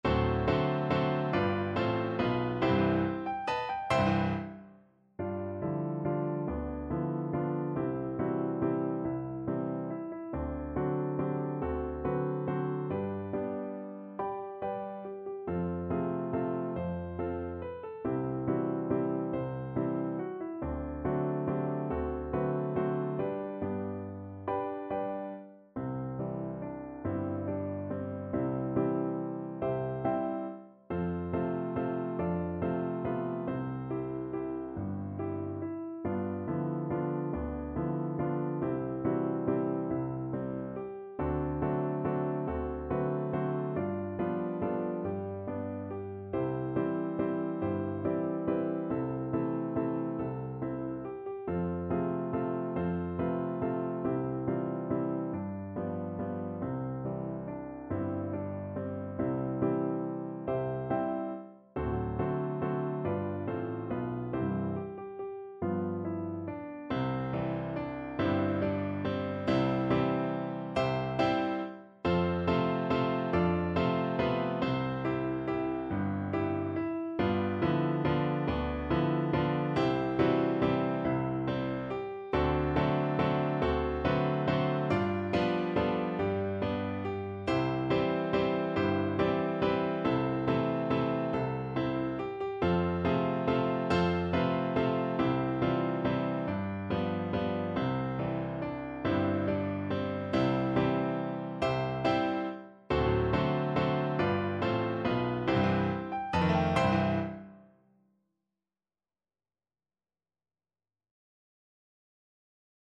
Piano version
No parts available for this pieces as it is for solo piano.
3/4 (View more 3/4 Music)
=140 Moderato Valse